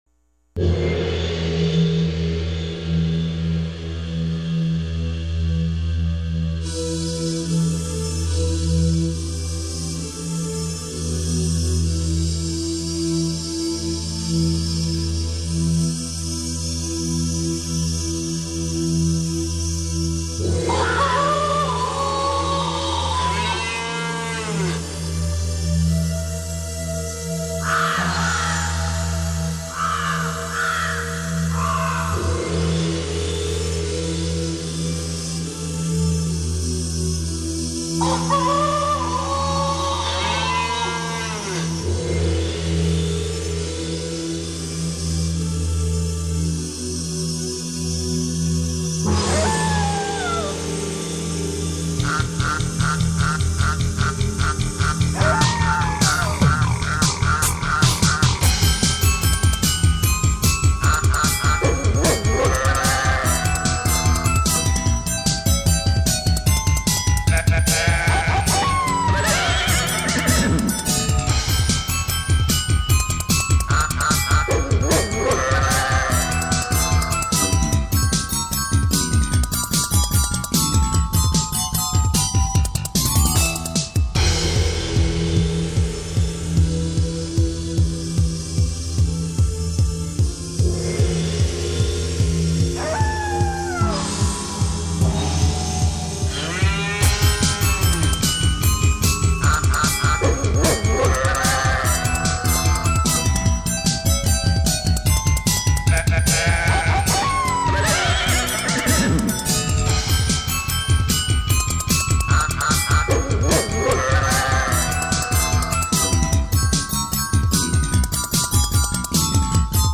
An instrumental, well, except for the crows. And the cow. The dog. And the rooster.